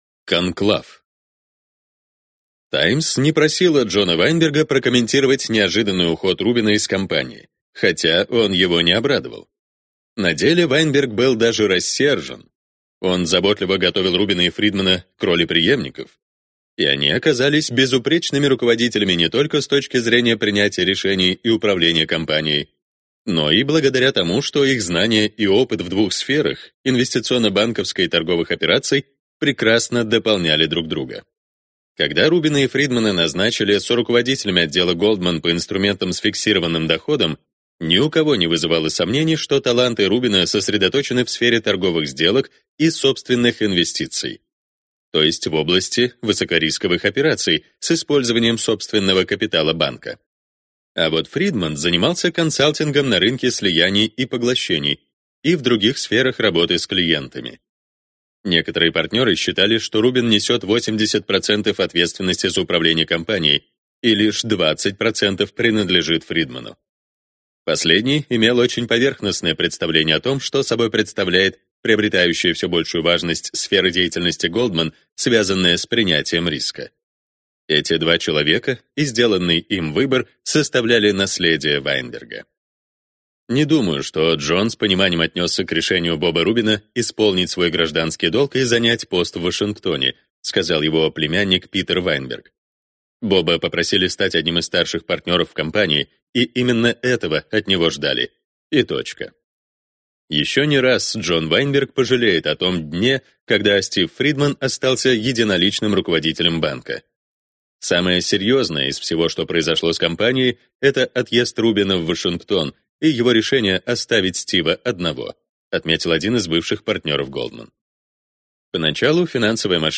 Аудиокнига Деньги и власть. Как Goldman Sachs захватил власть в финансовом мире. Часть 2 | Библиотека аудиокниг